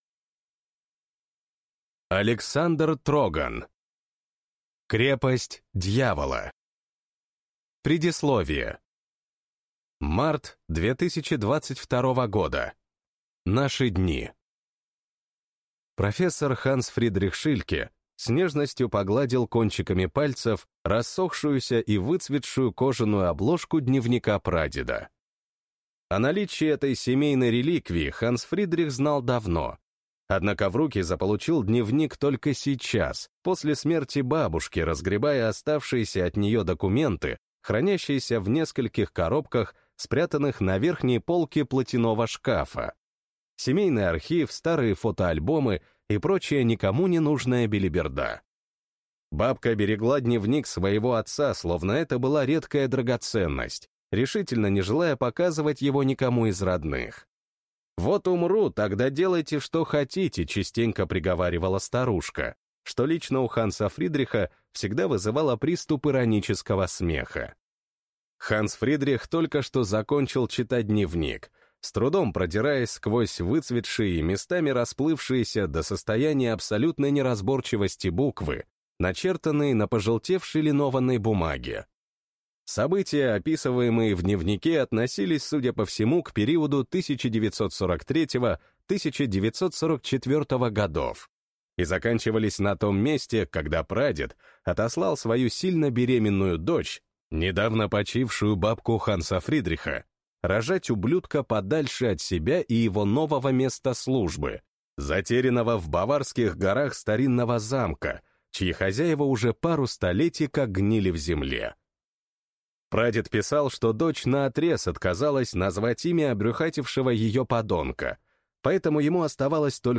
Аудиокнига Крепость дьявола | Библиотека аудиокниг